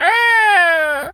pgs/Assets/Audio/Animal_Impersonations/seagul_squawk_hurt_03.wav at master
seagul_squawk_hurt_03.wav